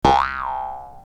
resources/phase_13/audio/sfx/bounce1.mp3 at e1639d5d6200f87d3312cfc8c8d1bbb1f69aad5f
bounce1.mp3